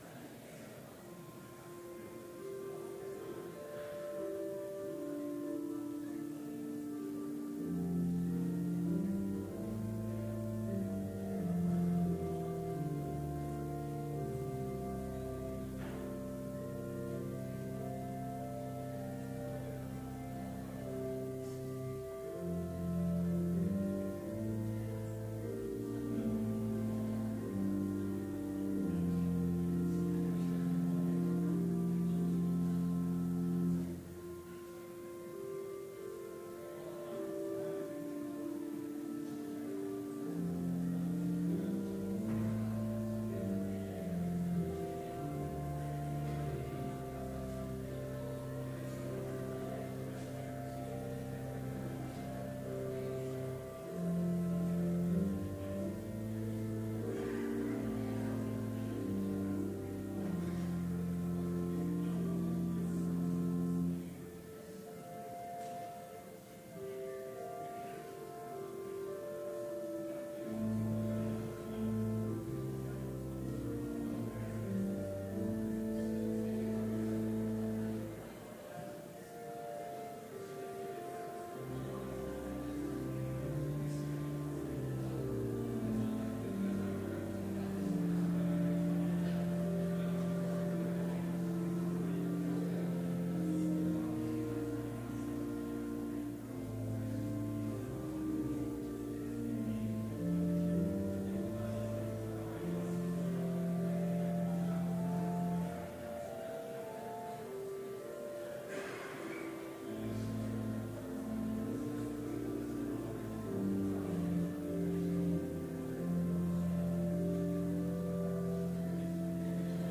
Complete service audio for Chapel - January 24, 2019